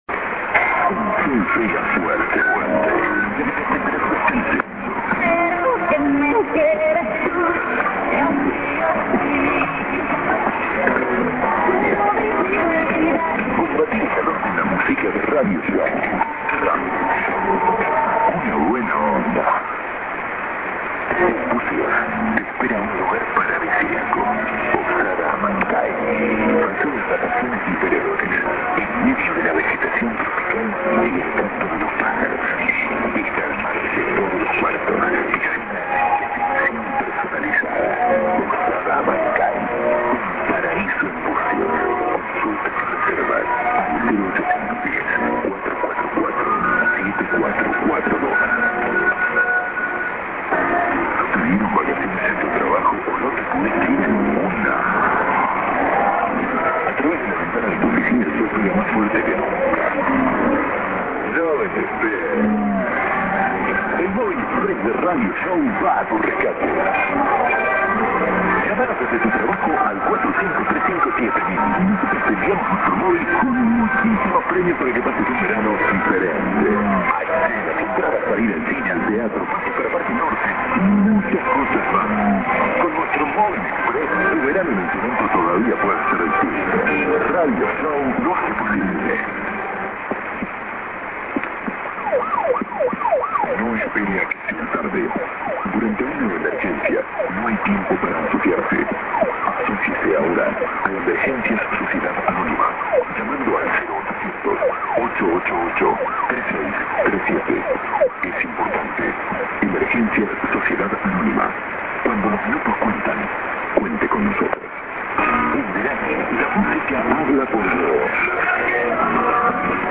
music-ID:Radio Show)X3(man)->music　結局２週間の週末に聞くことになりました。